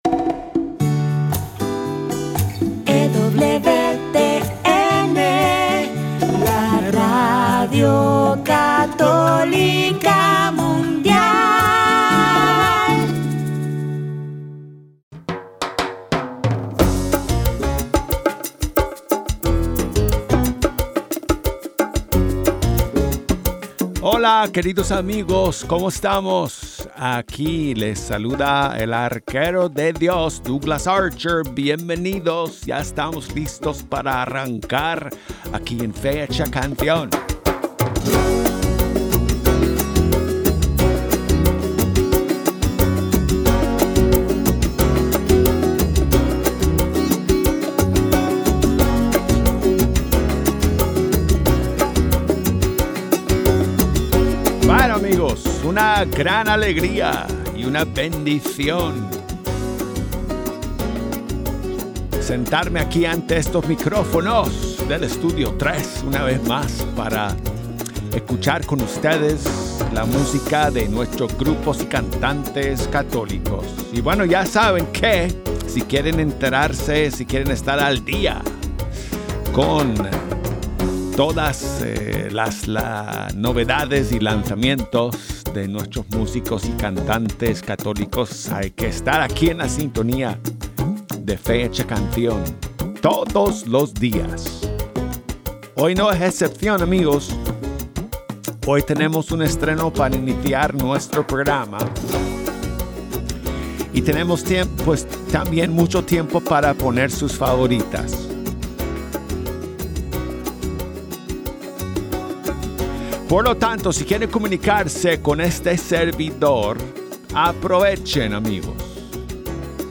una hora cargada de canciones